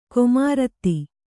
♪ komaratti